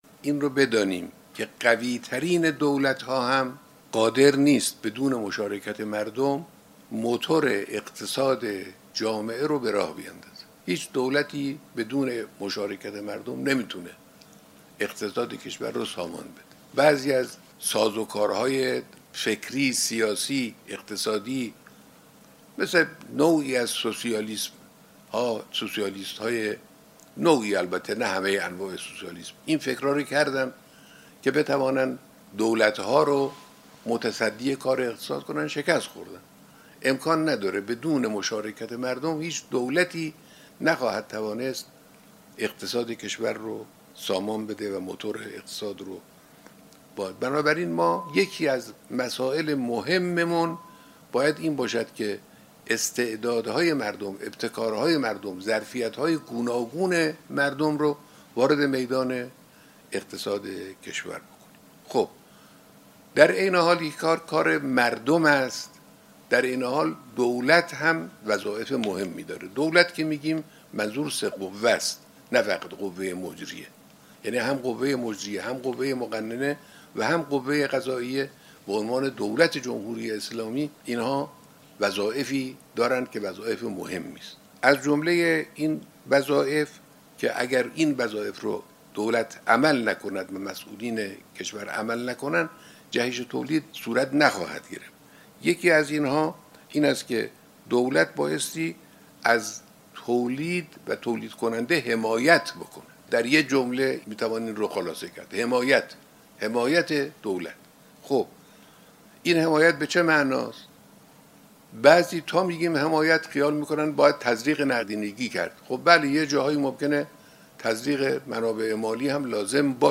بخشی از بیانات رهبر انقلاب در ارتباط تصویری با مجموعه‌های تولیدی به‌مناسبت هفته کارگر.